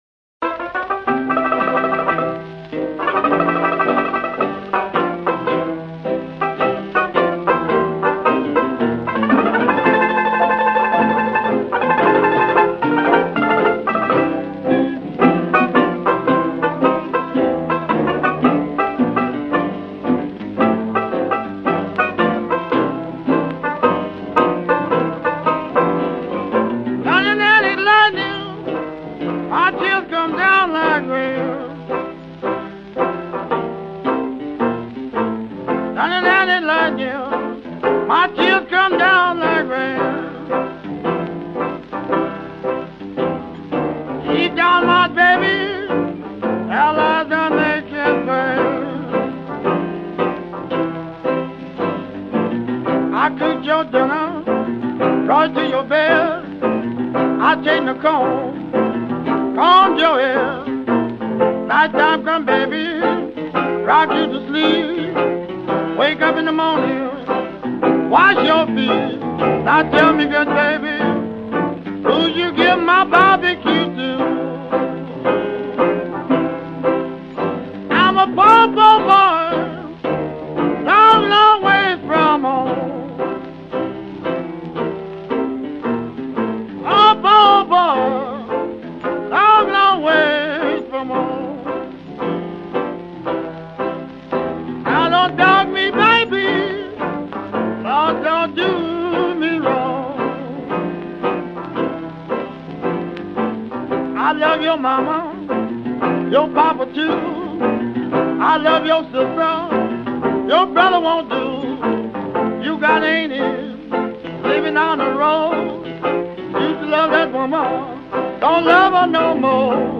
Instrumental Para Ouvir: Clik na Musica.